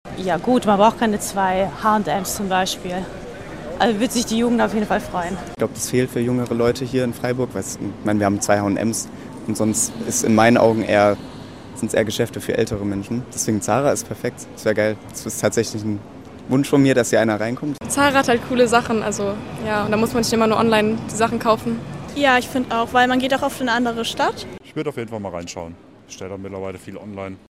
Eine Umfrage unter Passantinnen und Passanten in der Freiburger Innenstadt: